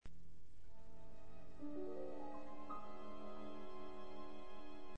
آکوردی رمز آمیز باصدای زیر که طلایه دار آغاز بخش میانی است، رؤیای کودک را مجسم می کند.